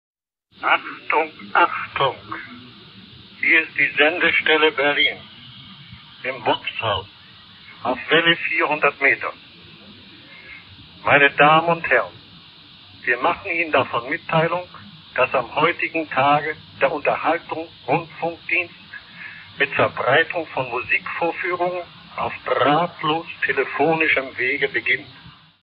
October 29  First German medium wave transmissions begin from the top of the Vox Haus in Berlin on 400m.